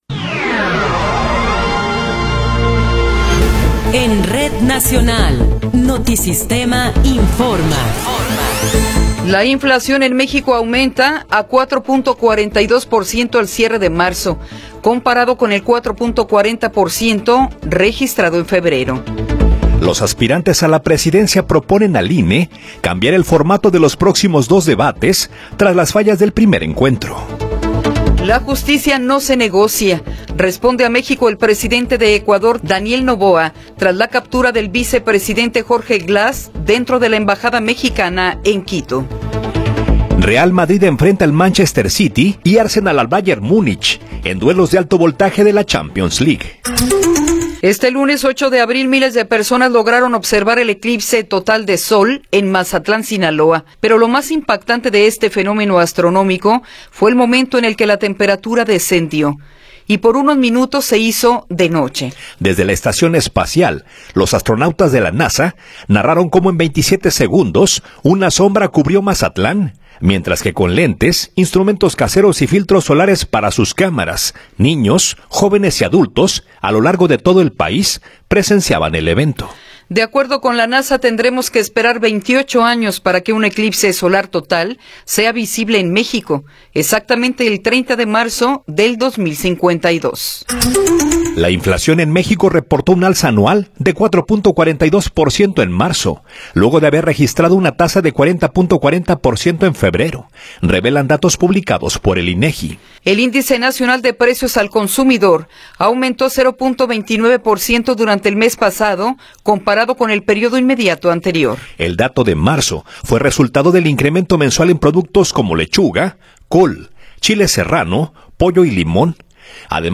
Noticiero 8 hrs. – 9 de Abril de 2024
Resumen informativo Notisistema, la mejor y más completa información cada hora en la hora.